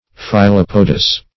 Search Result for " phyllopodous" : The Collaborative International Dictionary of English v.0.48: Phyllopodous \Phyl*lop"o*dous\ (f[i^]l*l[o^]p"[-o]*d[u^]s), a. (Zool.)